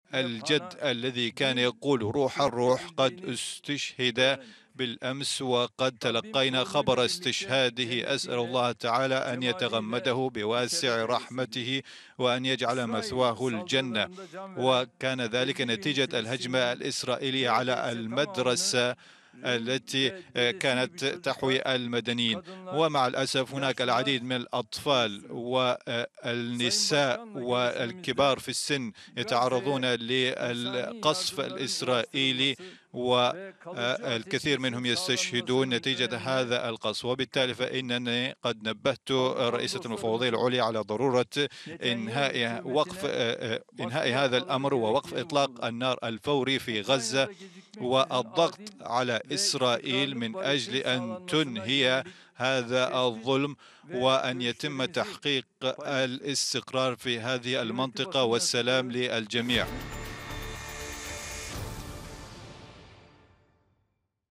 خلال مؤتمر صحفي مشترك مع رئيسة المفوضية الأوروبية بالعاصمة أنقرة